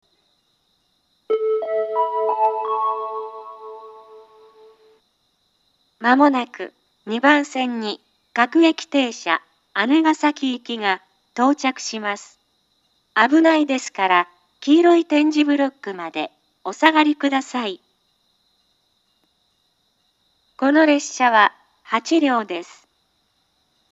２０１２年２月下旬頃には放送装置が更新され、自動放送が合成音声に変更されました。
２番線接近放送A
また、脇を走る小湊鉄道の列車の走行音がたまに被ることがあります。